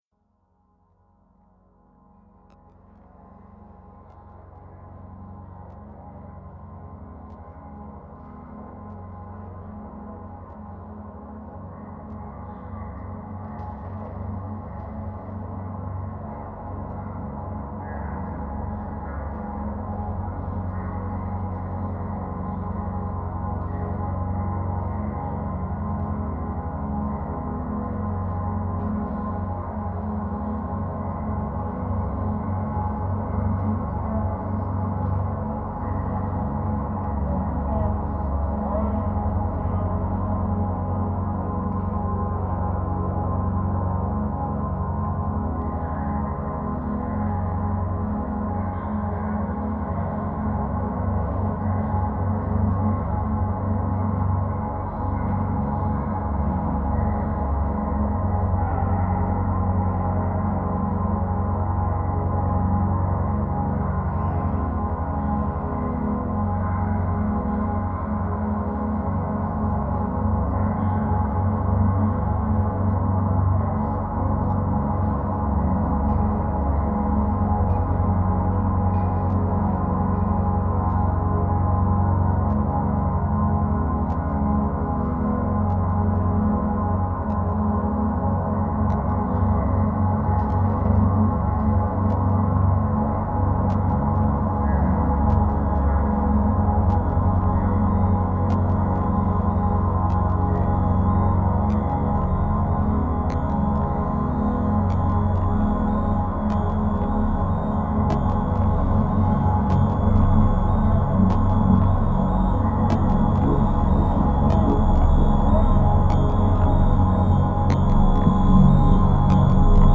Genre: Dub Techno/Ambient/Drone/Techno.